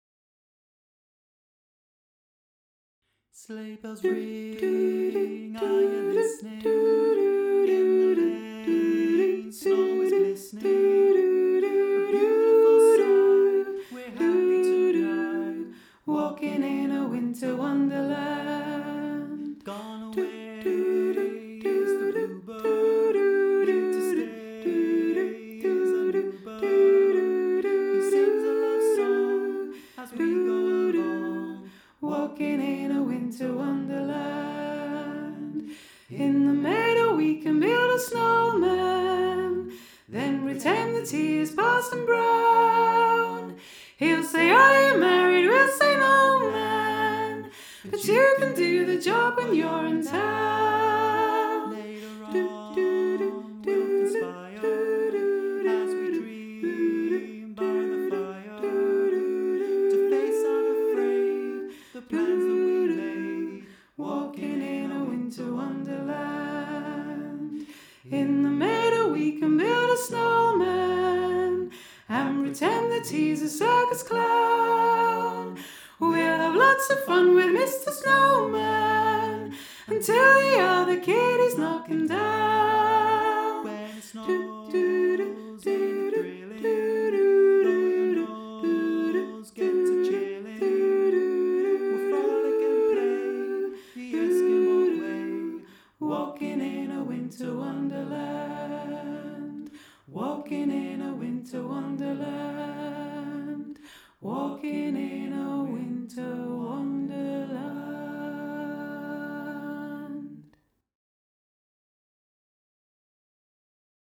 Winter-Wonderland-Soprano.mp3